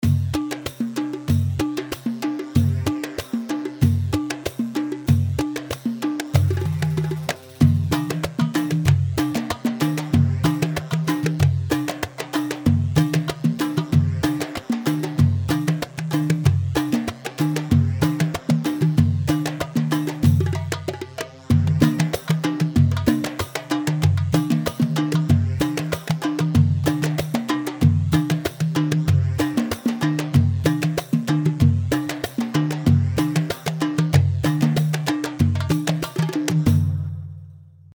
Yemeni
Bdwi Hobsh 4/4 190 بدوي حبيش
Badawi-Hobesh-4-4-190.mp3